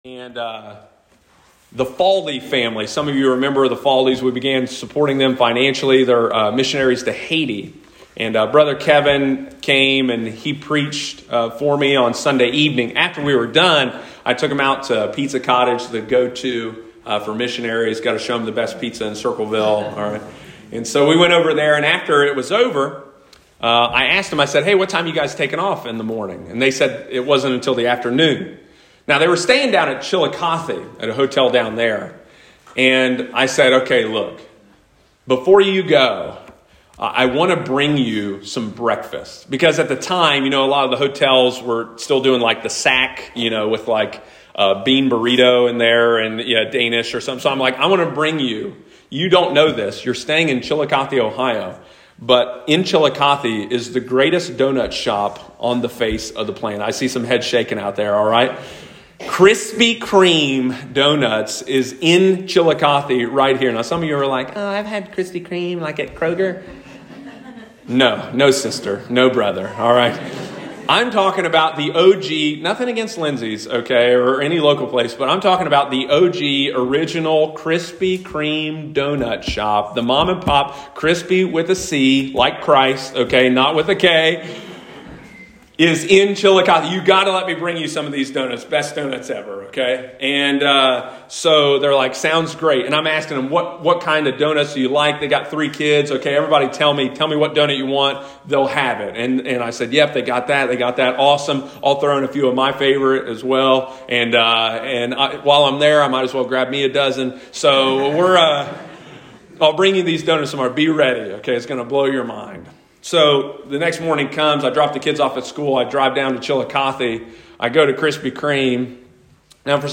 How do we reconcile the modern message with the ancient reality of the church? And how can we rejoice in the midst of our trials? Sunday morning, March 27, 2022.